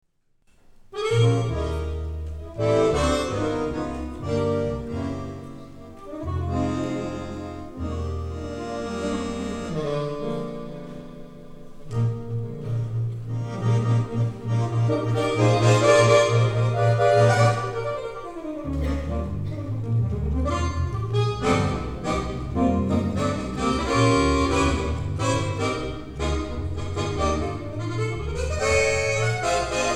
eTrack Music from the 2009 Coupe Mondiale Finale Concert